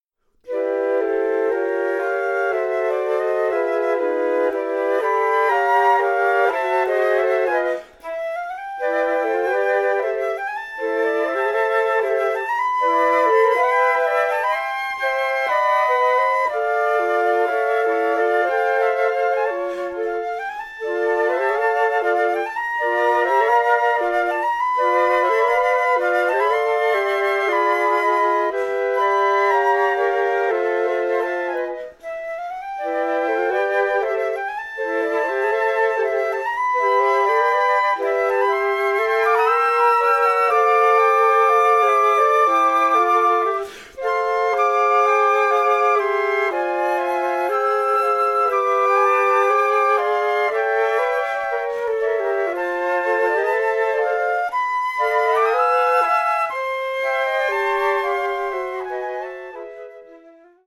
Pour quatuor de flûtes